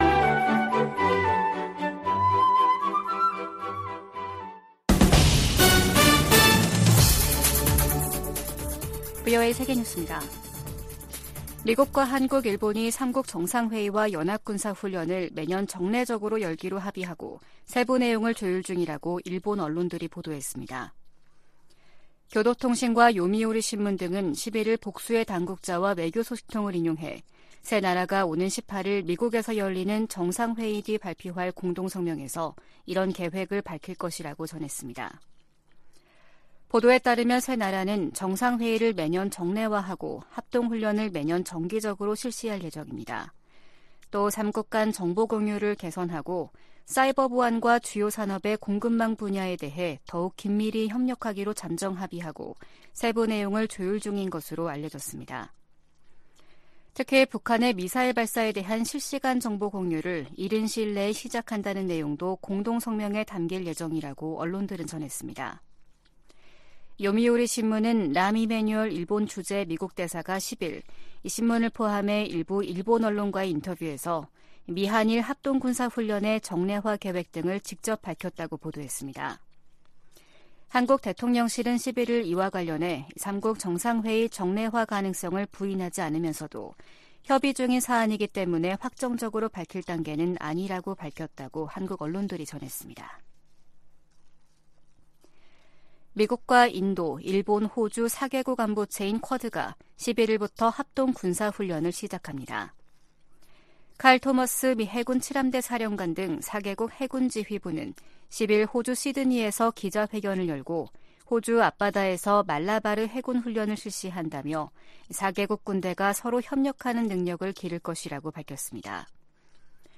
VOA 한국어 아침 뉴스 프로그램 '워싱턴 뉴스 광장' 2023년 8월 12일 방송입니다. 미국과 한국·일본 등이 유엔 안보리에서 북한 인권 문제를 공개 논의를 요청했습니다. 미 국방부가 북한의 추가 도발 가능성과 관련해 한국·일본과 긴밀하게 협력하고 있다고 밝혔습니다. 북한 해킹조직이 탈취한 미국 내 암호화폐 자산을 동결 조치할 것을 명령하는 미 연방법원 판결이 나왔습니다.